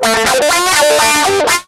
gtdTTE67023guitar-A.wav